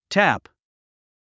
発音
tǽp　タプ